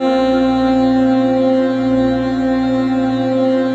DM PAD2-92.wav